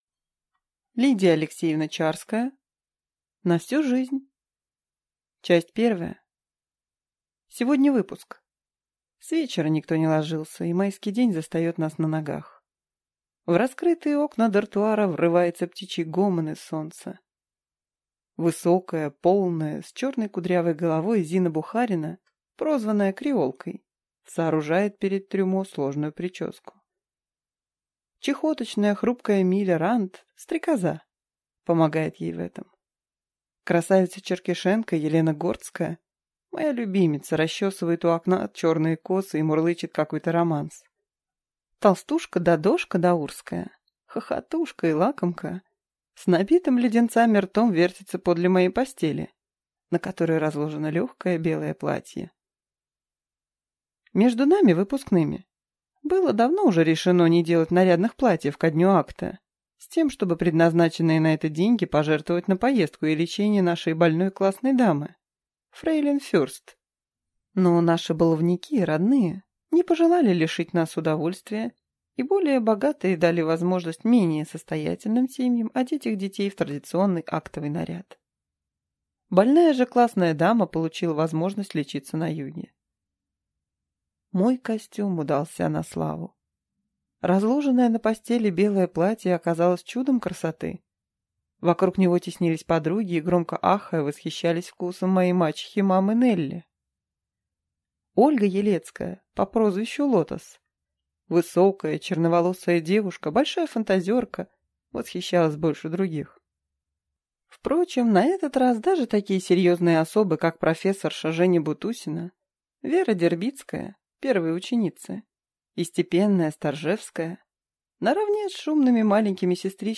Аудиокнига На всю жизнь | Библиотека аудиокниг